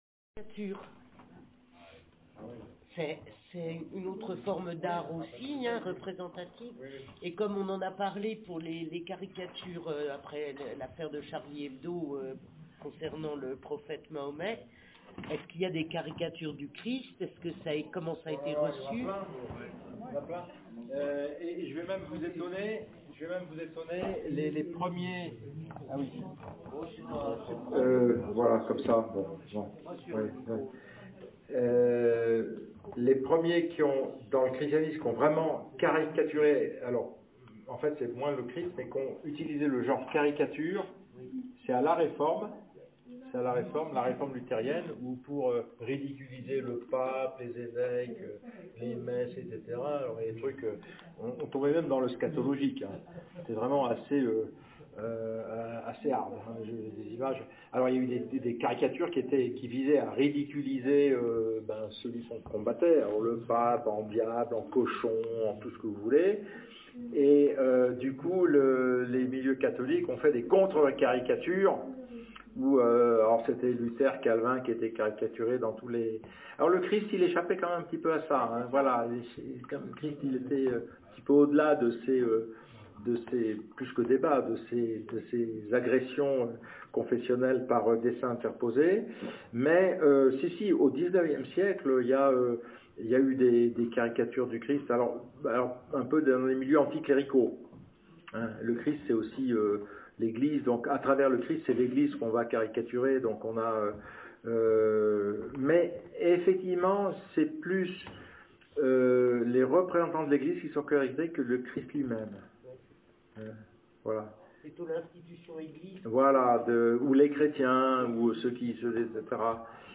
Conférence le Jeudi 19 septembre 2024 à 20h 30 à la Maison du Peuple
La discussion peut être ré-écoutée en cliquant sur le lien ci dessous: